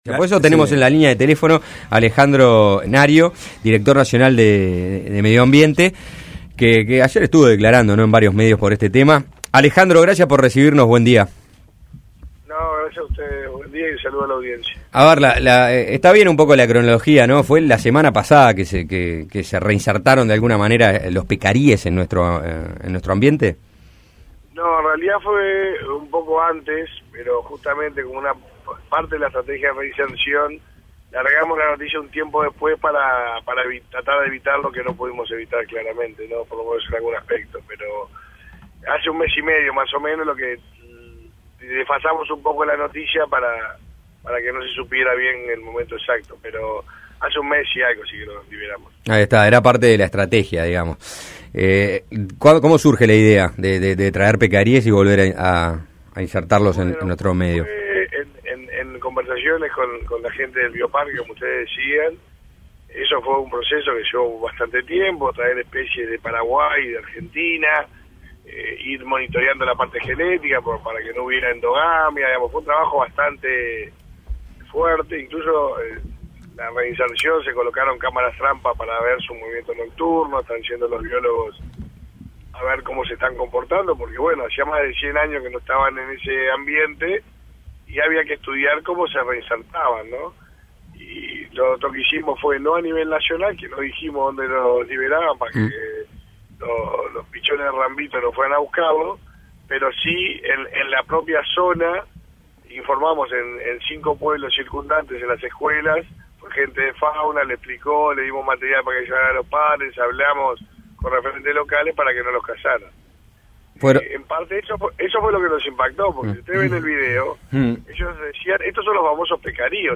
El director nacional de Medio Ambiente, Alejandro Nario, explicó cómo fue el caso de los hombres detenidos y procesados por la caza furtiva de pecaríes, una especia extinta que se estaba reintroduciendo en Uruguay.